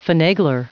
Prononciation du mot finagler en anglais (fichier audio)
Prononciation du mot : finagler